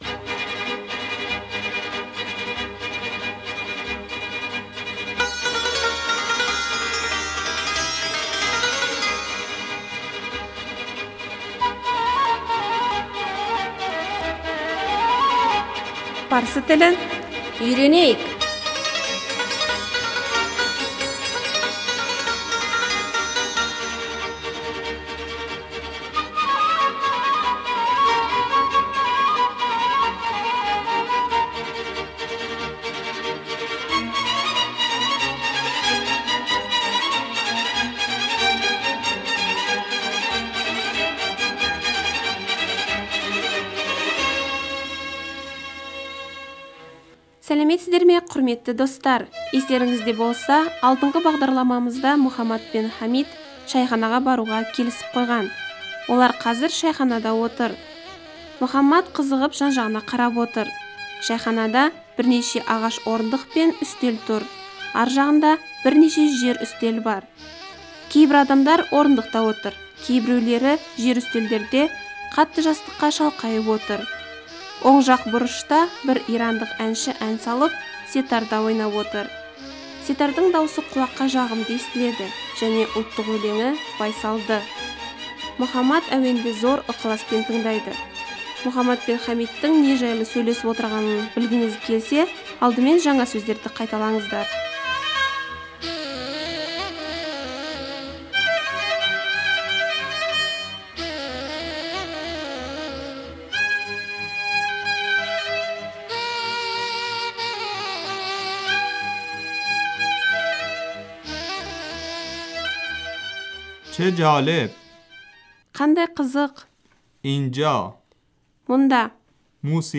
Оң жақтың бұрышында бір ирандық әнші ән салып , сетарда (үш шек құралы) ойнап отыр. Сетардың дауысы құлаққа жағымды және ұлттық өлеңі байсалды.